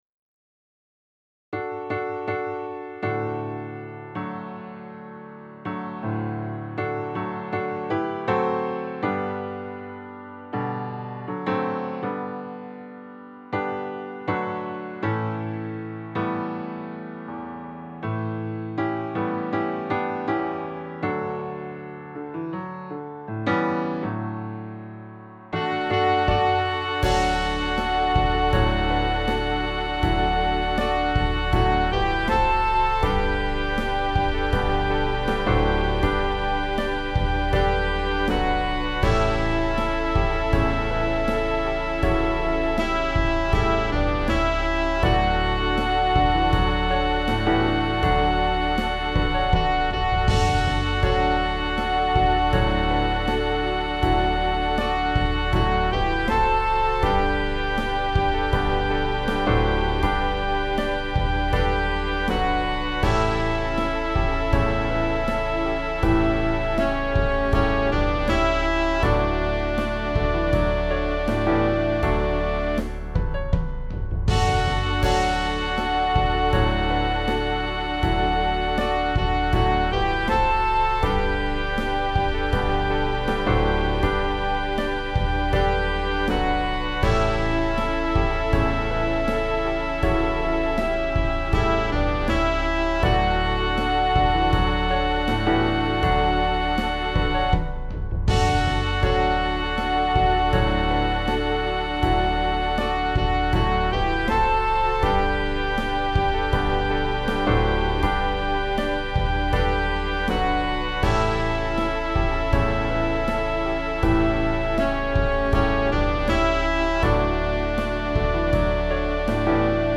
MP3 instrumental